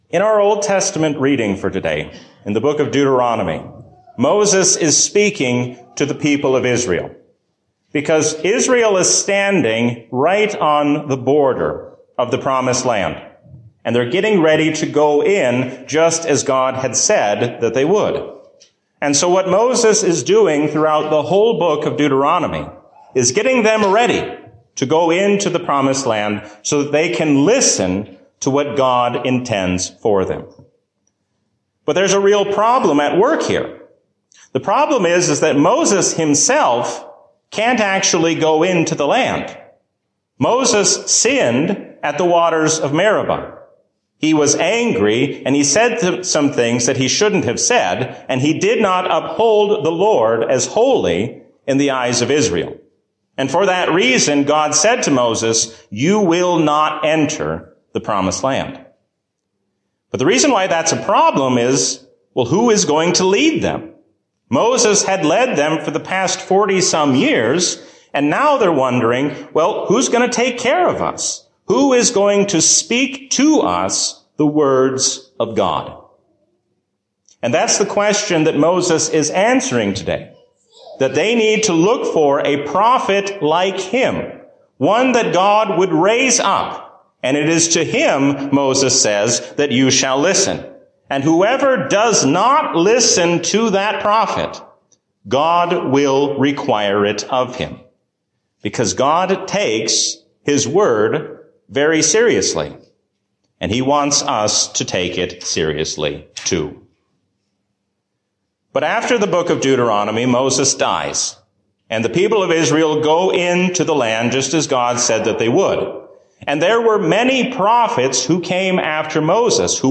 A sermon from the season "Trinity 2024." Doing what God says means following after Him without making excuses.